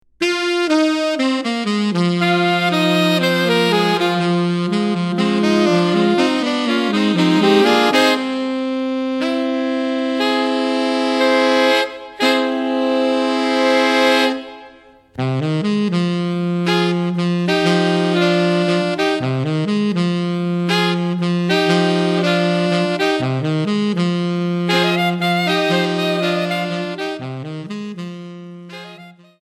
4 Saxophones (2A2T)